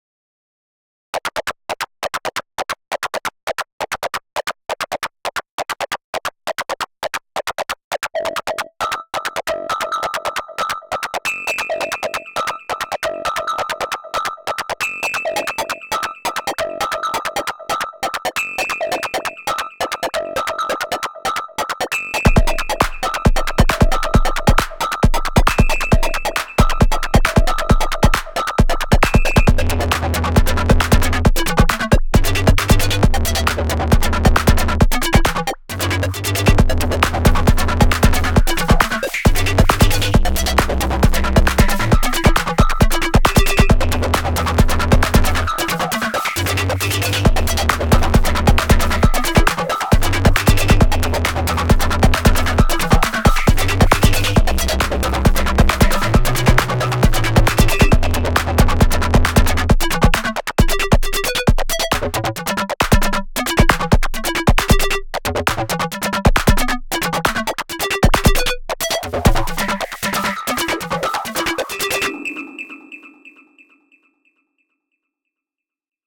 This pattern is seven tracks of CP vintage with the first track comprising the kick and snare sound. The FX track is ducking the amplifier with some Euclidian rhythm excluding the first track to fake some compression.
Everything else is routed through the FX with the drive parameter at maximum. Each track is at varying degrees of overdrive on the SYN page. Anything that is tonal has either a high pass or low pass filter with 100% tracking on it.
That crunchy chirp sound that joins third has an LFO on the BODY parameter.
:grinning: Nice bouncing beats and energy!